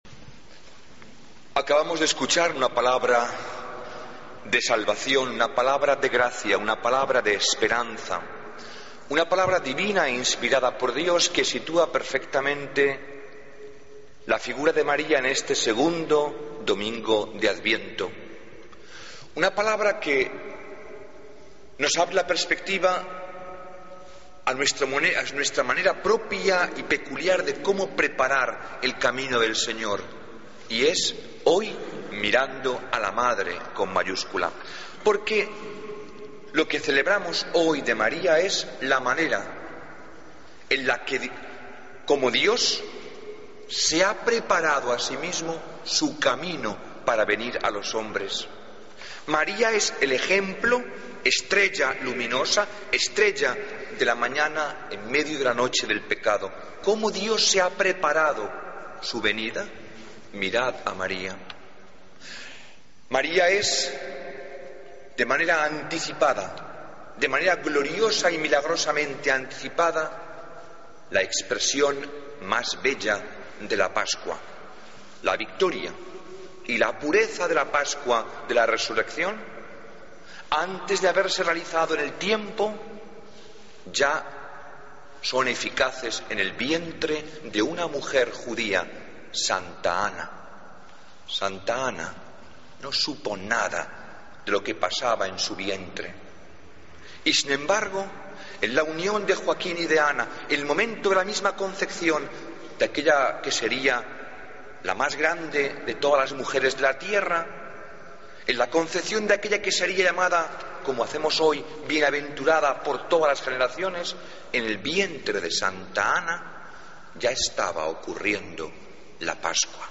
Homilía del 8 de Diciembre de 2013